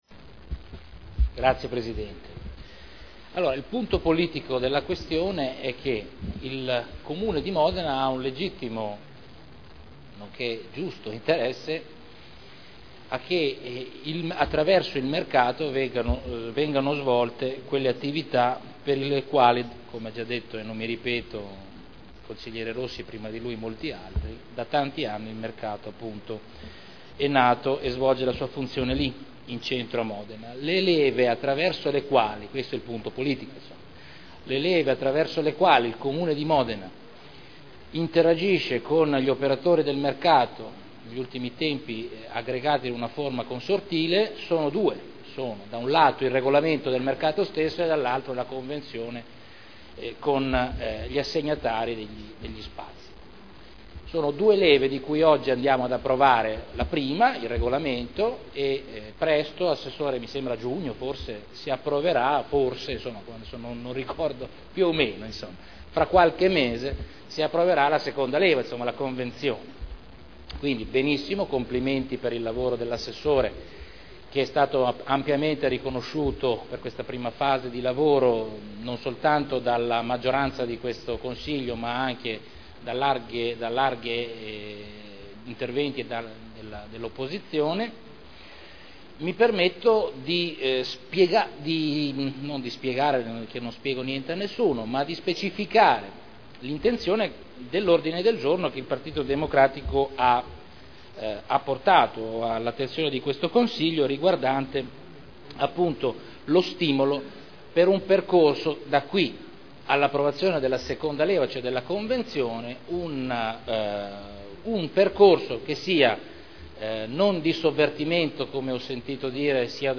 Salvatore Cotrino — Sito Audio Consiglio Comunale
Seduta del 17/01/2011. Regolamento comunale del mercato coperto quotidiano di generi alimentari denominato “Mercato Albinelli” – Approvazione modifiche Discussione